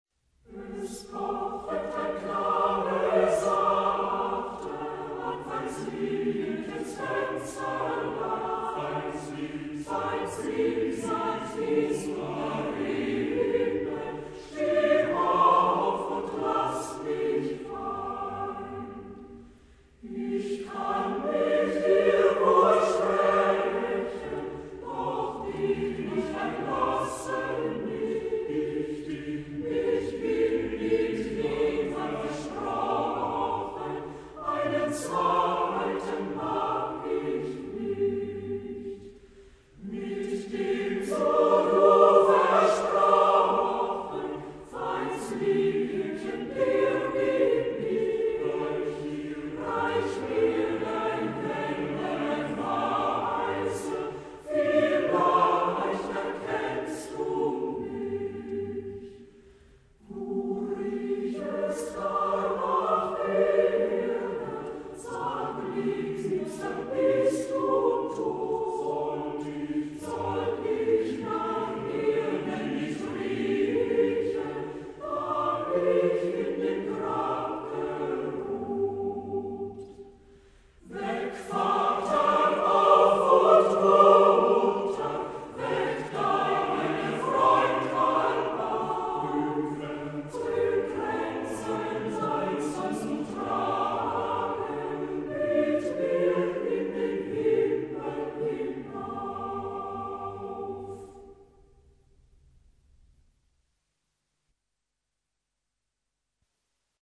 OEUVRES CHORALES - CHORWERKE - LIEDERS opus 31, 64, 92, 112
certains fichiers mp3 sont en 22Khz mono